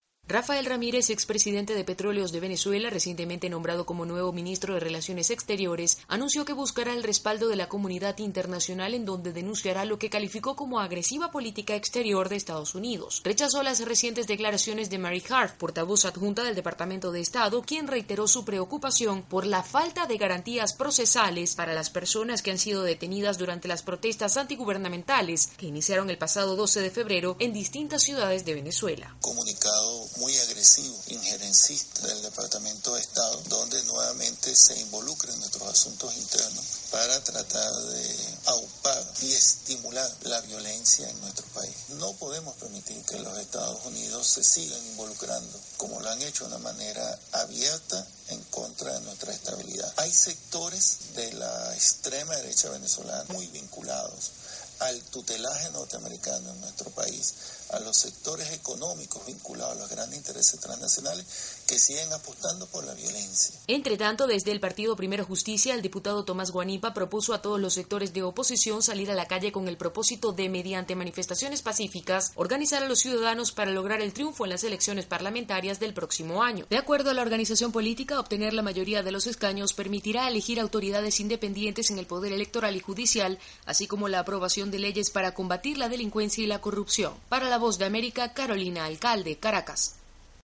El nuevo canciller venezolano acusó al gobierno de Estados Unidos de intentar desestabilizar al país. Desde Caracas informa